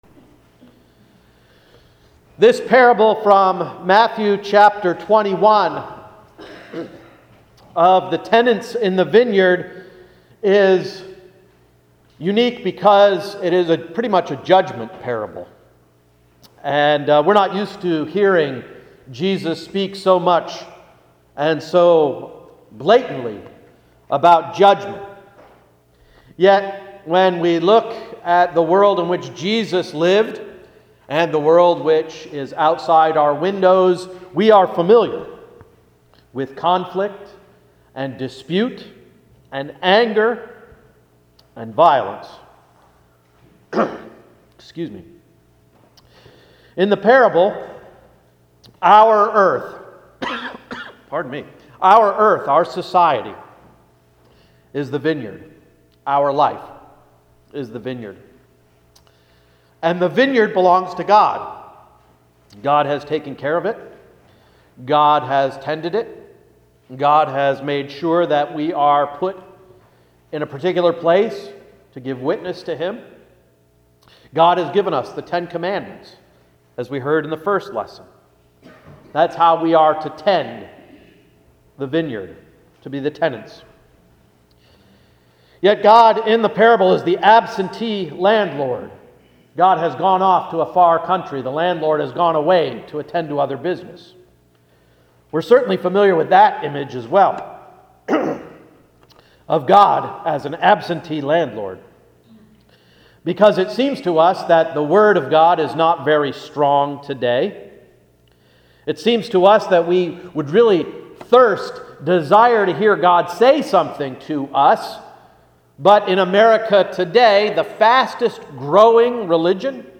Sermon of October 5, 2014–“Jesus: The Lamb of God”